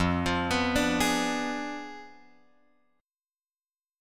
F Diminished 7th